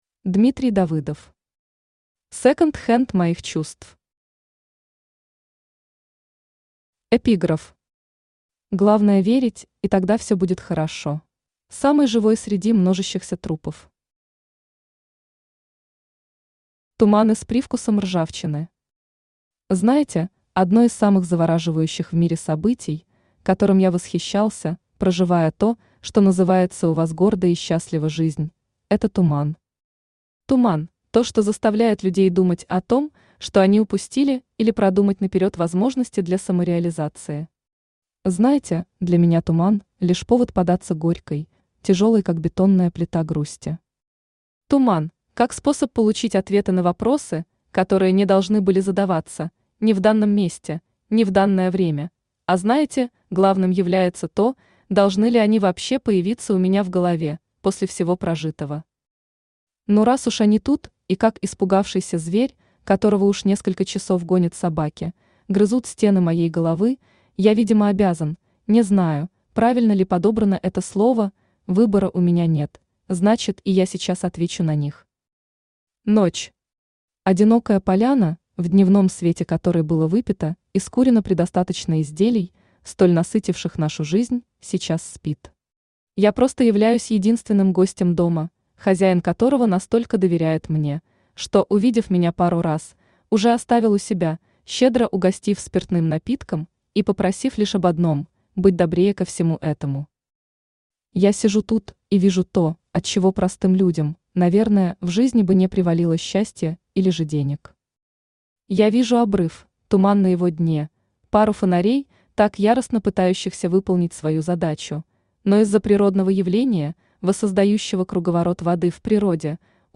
Аудиокнига Секонд-хенд моих чувств | Библиотека аудиокниг
Aудиокнига Секонд-хенд моих чувств Автор Дмитрий Олегович Давыдов Читает аудиокнигу Авточтец ЛитРес.